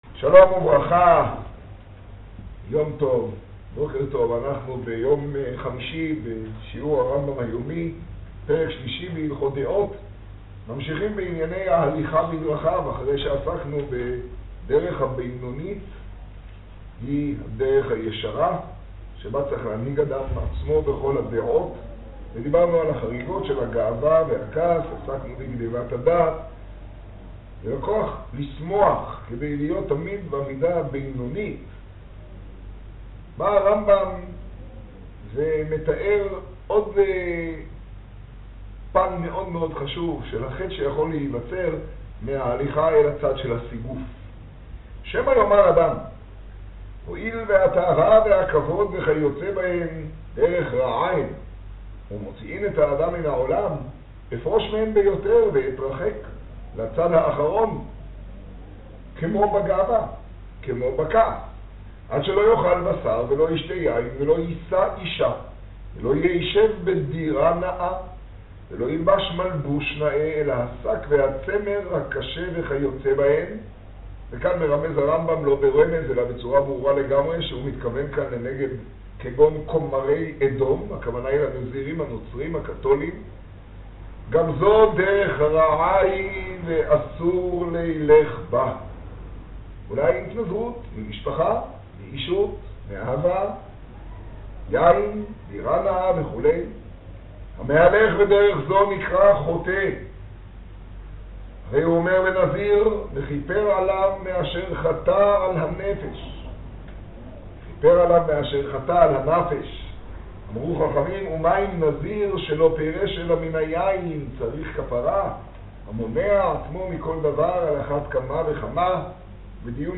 השיעור במגדל, יז טבת תשעה.
האזנה קטגוריה: מגדל - רמבם יומי , שיעור , תוכן תג: רמבם יומי , תשעה → ששים שנה רק תפילה היום בפרשה – חמישי של שמות תשעה ←